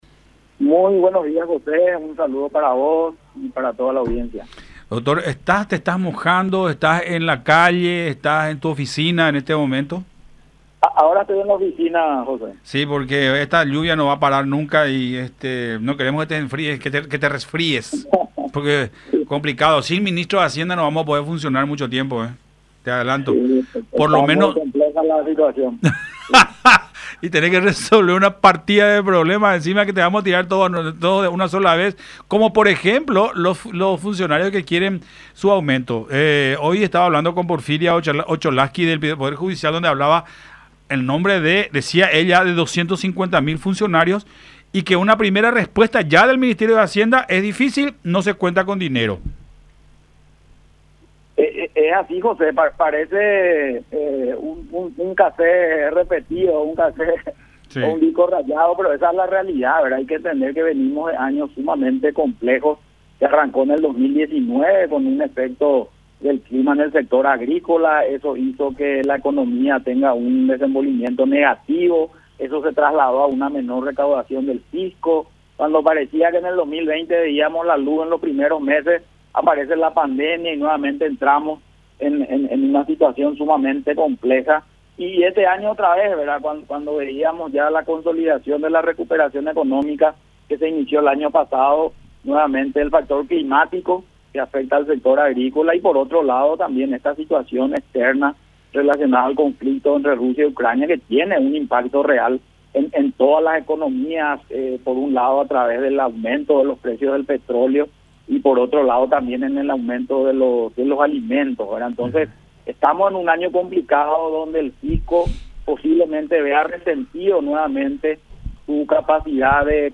No queremos que la salud de nuestra economía esté en riesgo”, explicó Llamosas en diálogo con Todas Las Voces por la Unión.